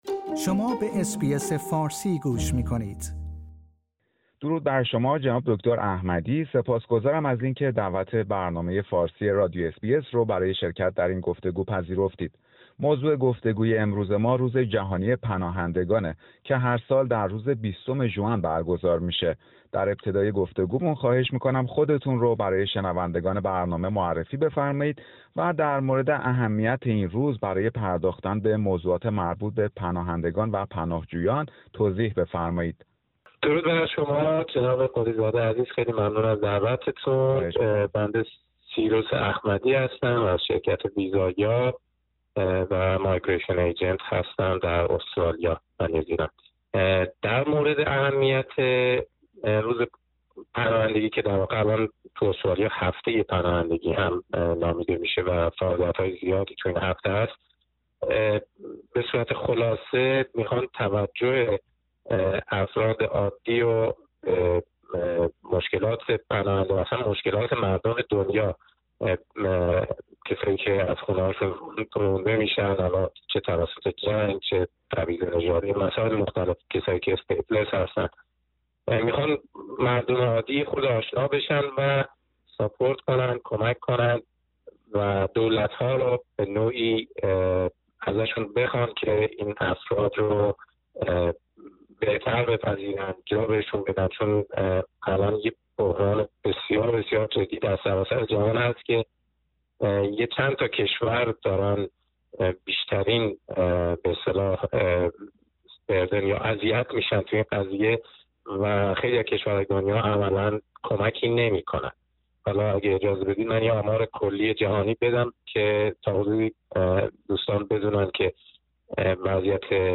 روز جهانی پناهندگان و گفتگویی در مورد وضعیت نامشخص بسیاری از پناهندگان و پناهجویان در استرالیا